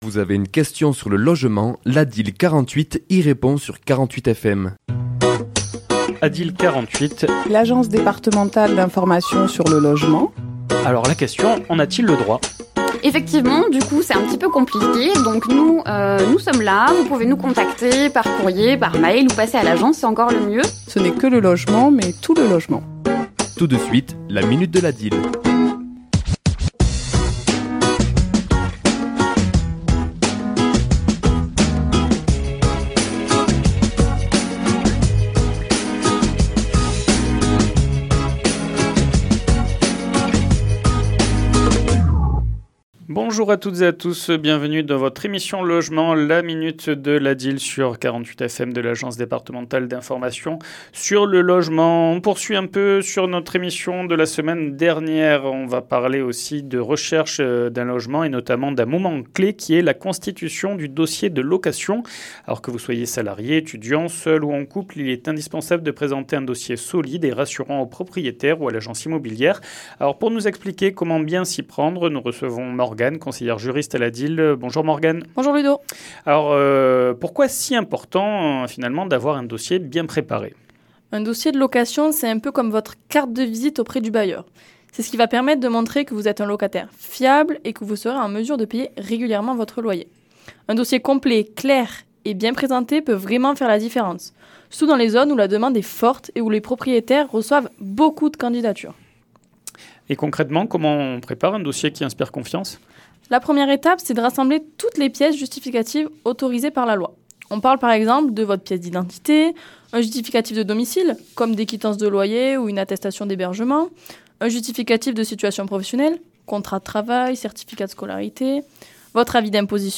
Chronique diffusée le mardi 18 novembre à 11h et 17h10